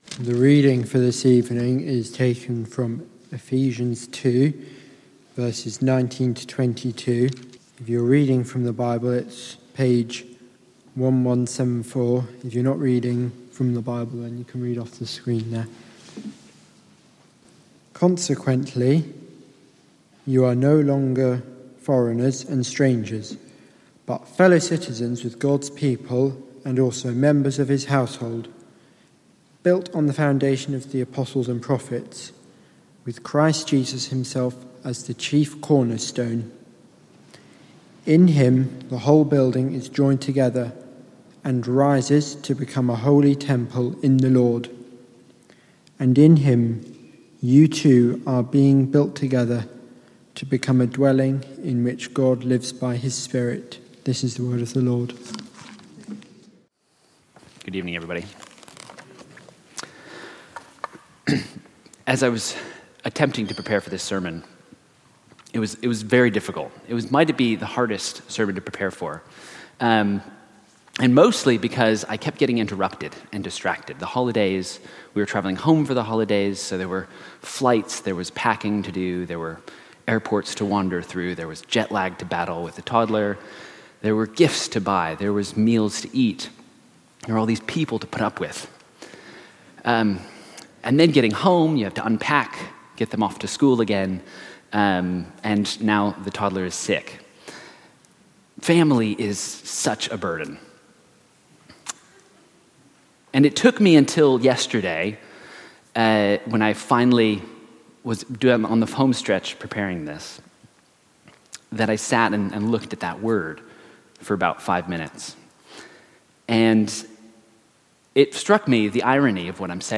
From Service: "6:00 pm Service"